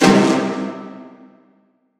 DDW4 ORCHESTRA 2.wav